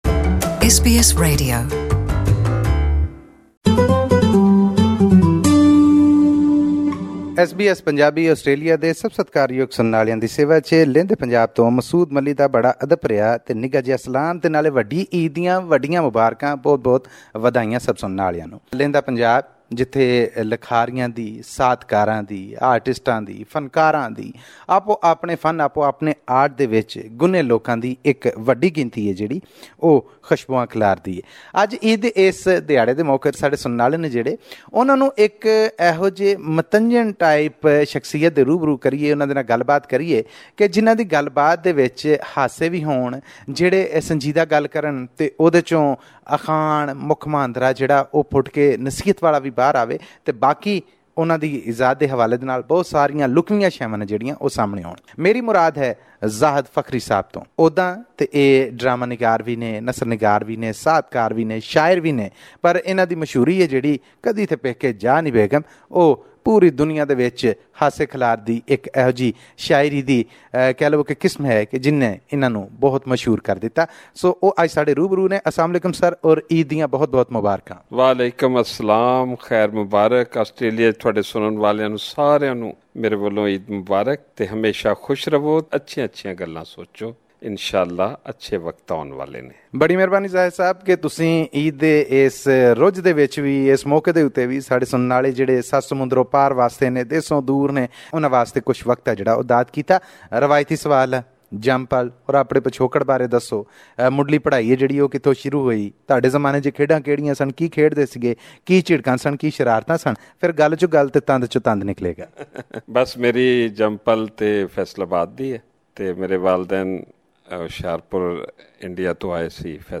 Interview with famous poet who wrote ‘Kadi te peke ja ni begum’